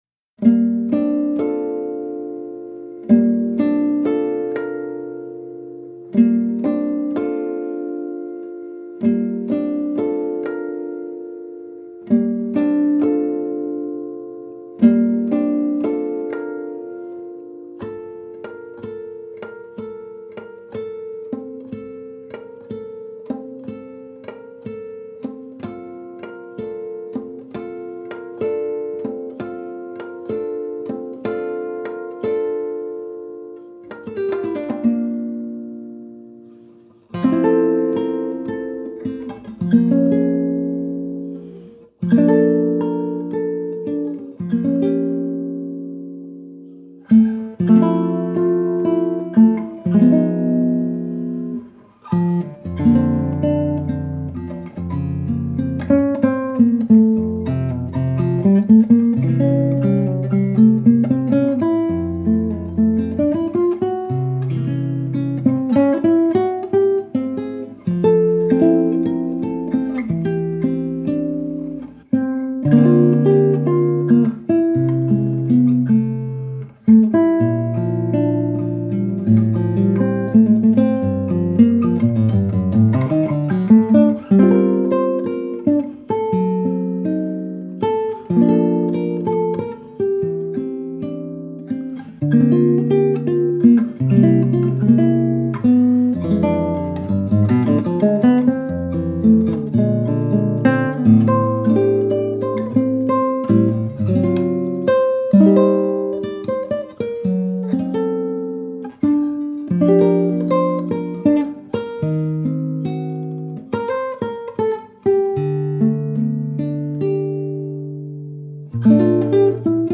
Tags: Violão Solo